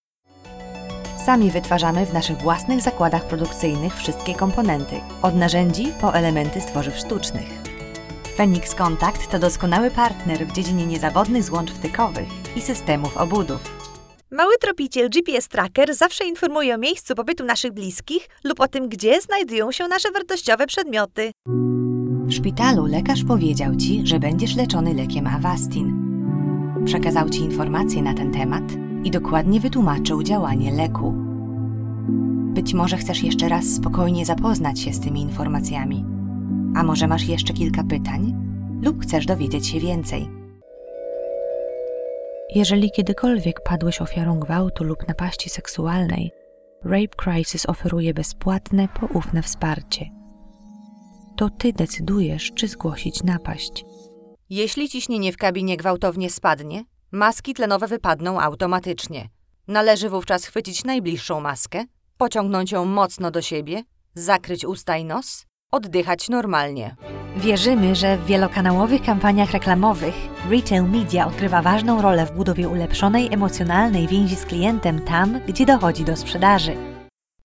Freundlich und zuverlässig, fröhlich und kindlich oder autoritativ und ernst, meine Stimme ist perfekt für Werbung und Video Explainers (Englisch UK und Polnisch)
Sprechprobe: Industrie (Muttersprache):
Polish - Narrative Reel_0.mp3